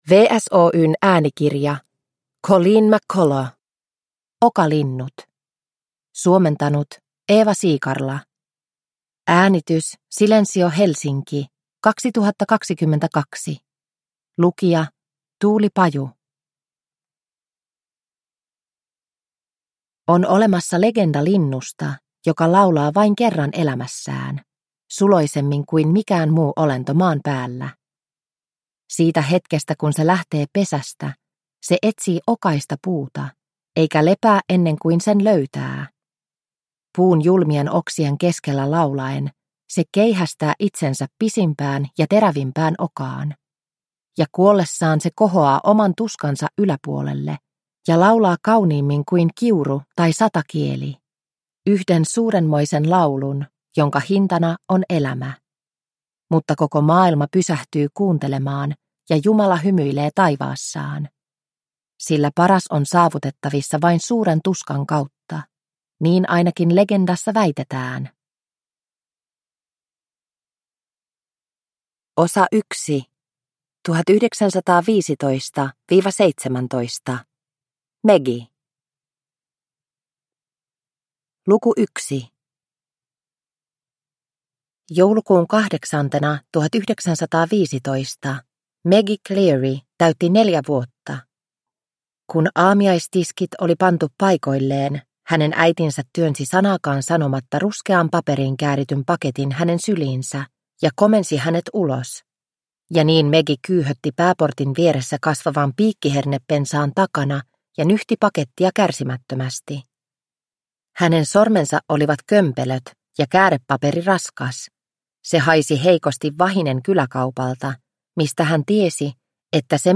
Okalinnut – Ljudbok – Laddas ner